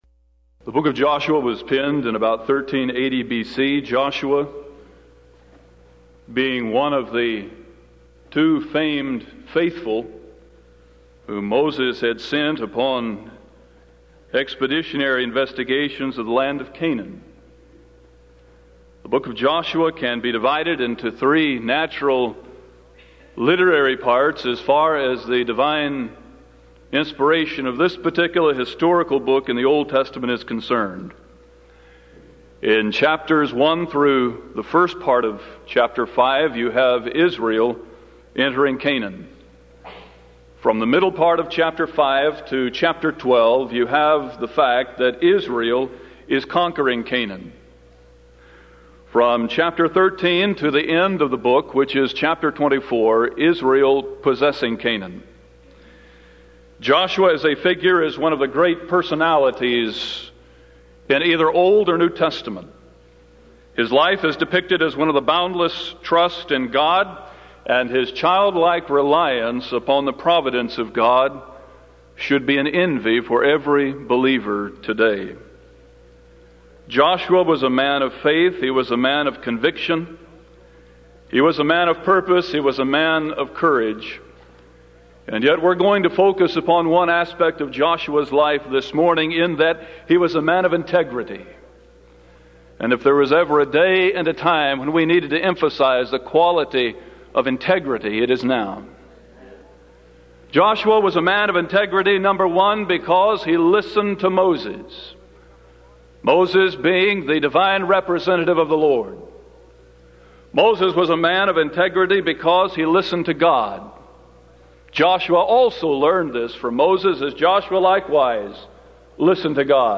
Event: 1994 Denton Lectures
lecture